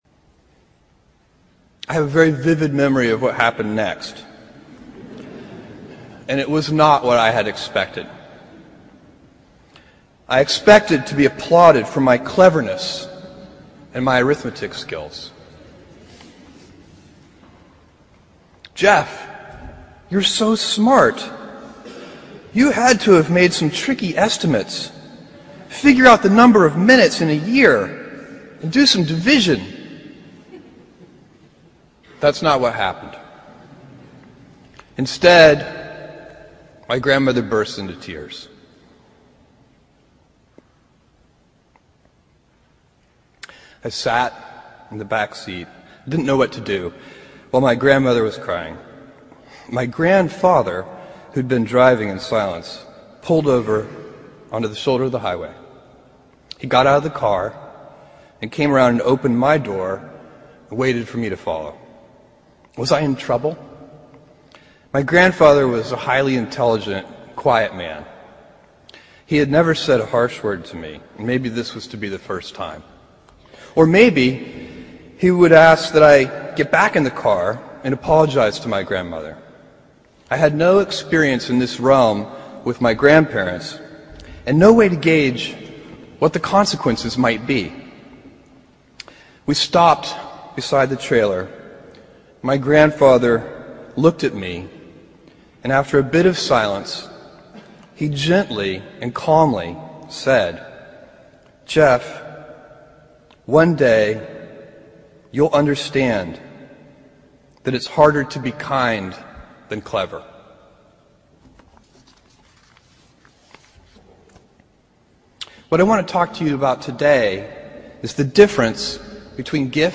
公众人物毕业演讲 第303期:亚马逊创始人贝佐斯普林斯顿大学毕业演讲(2) 听力文件下载—在线英语听力室